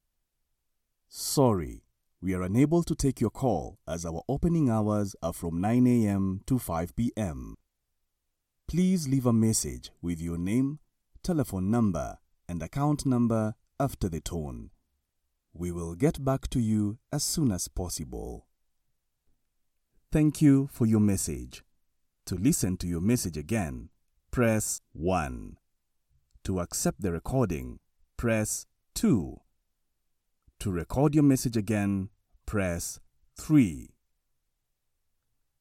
Englisch (afrikanisch)
IVR
TiefNiedrig
ReifenWarmLustigAutorisierendEmotional